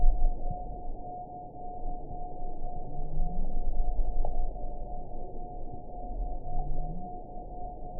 event 910226 date 01/16/22 time 22:05:59 GMT (3 years, 5 months ago) score 8.33 location TSS-AB06 detected by nrw target species NRW annotations +NRW Spectrogram: Frequency (kHz) vs. Time (s) audio not available .wav